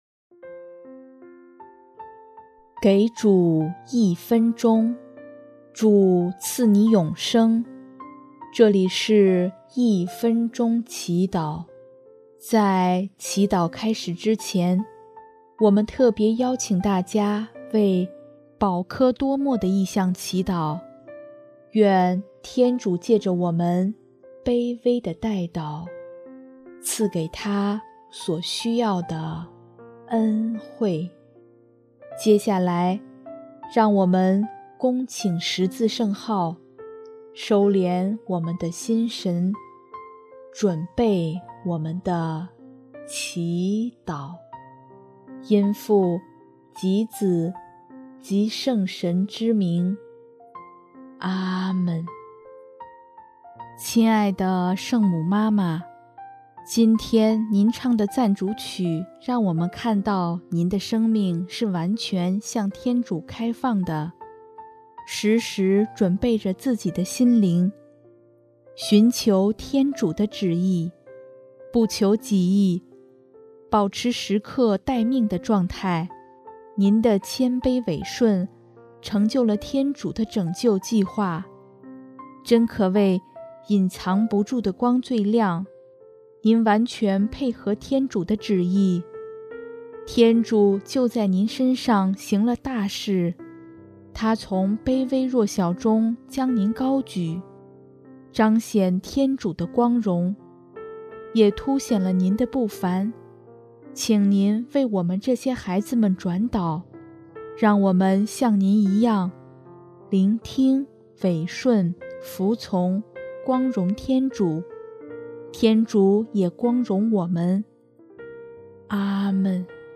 【一分钟祈祷】|12月22 全能者在我身上行了大事